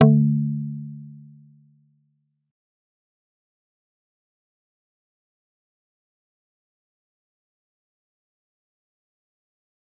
G_Kalimba-D3-mf.wav